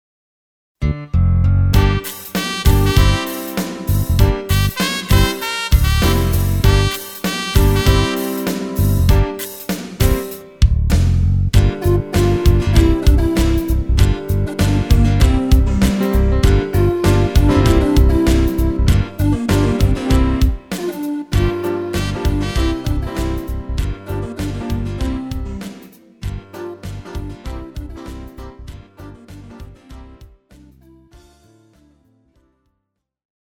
KARAOKE/FORMÁT:
Žánr: Pop